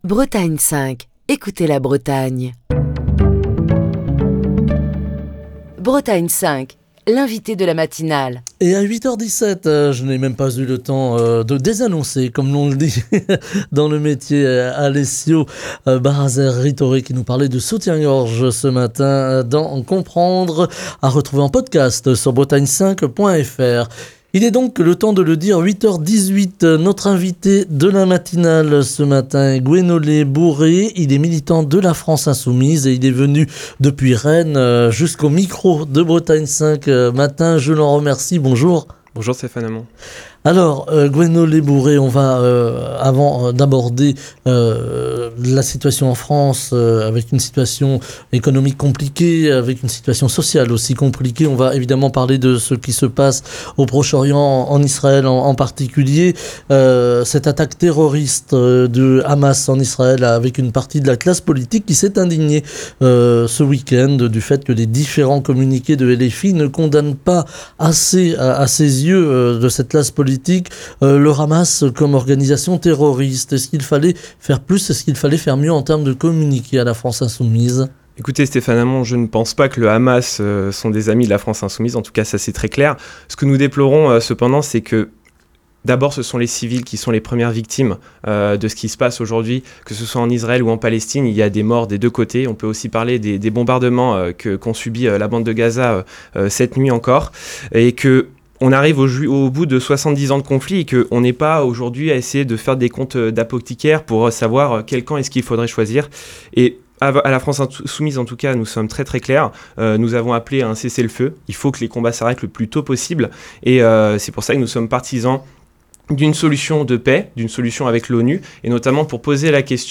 Émission du 9 octobre 2023. Ce matin l'actualité nous emmène en Israël où le Hamas a conduit ce week-end une attaque sans précédent. En France, une partie de la classe politique s'est indignée du fait que les différents communiqués de LFI ne condamnaient pas le Hamas comme organisation terroriste, reprochant une certaine ambigüité de la part de La France Insoumise.